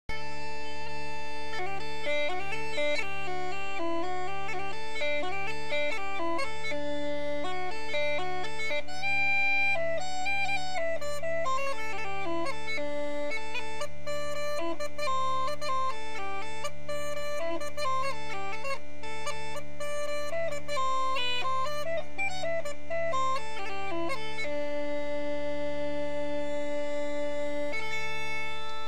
PipingUilleann Pipes
Some sample clips (.wma) of my piping